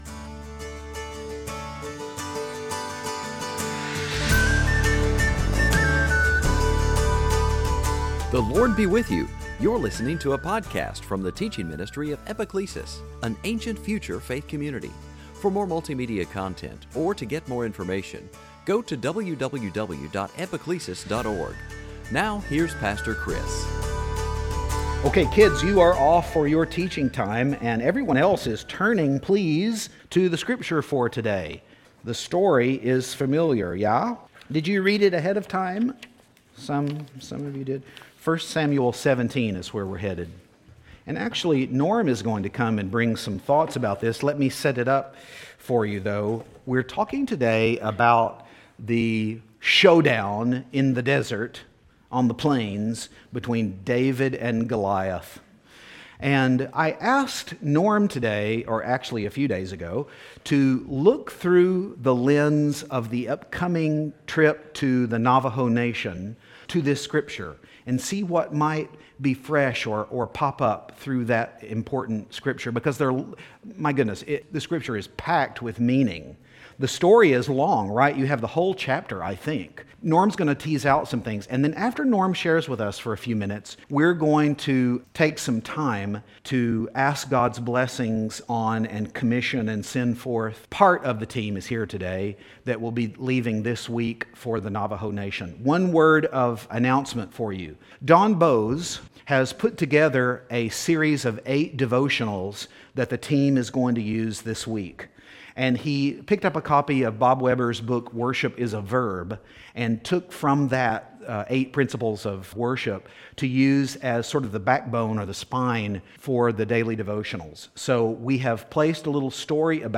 Service Type: Season after Pentecost